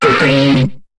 Cri de Ramboum dans Pokémon HOME.